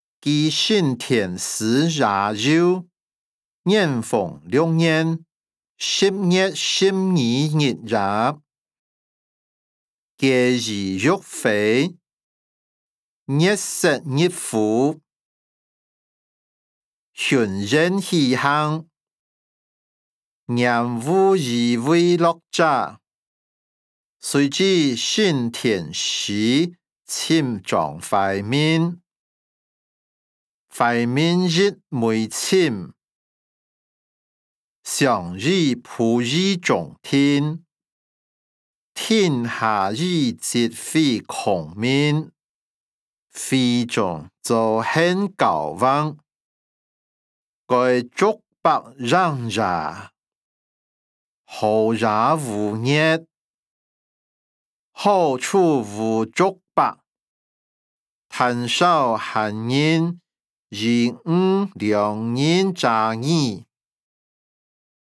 歷代散文-記承天寺夜遊音檔(饒平腔)